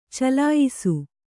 ♪ calāyisu